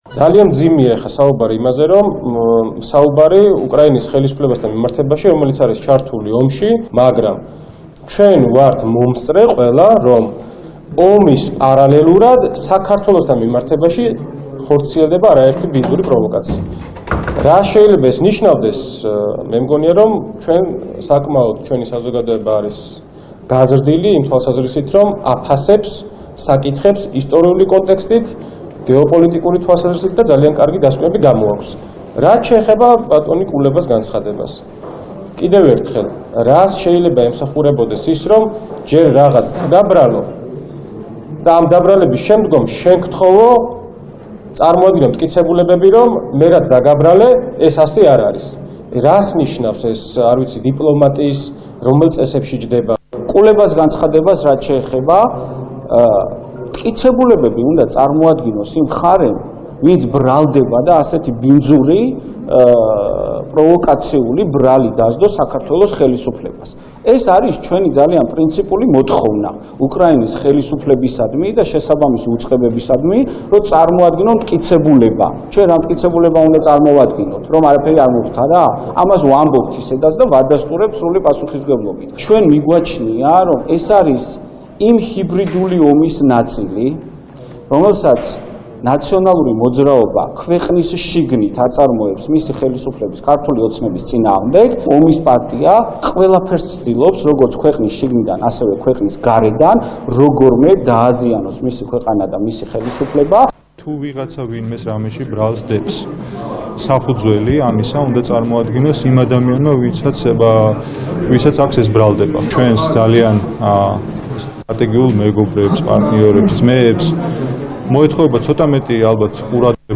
მამუკა მდინარაძის ხმა ბრიფინგიდან
ანრი ოხანაშვილის, გიორგი ამილახვარის ლევან დავითაშვილის თეა წულუკიანის რომან გოცირიძის ლევან იოსელიანის მიხეილ დაუშვილის ხმა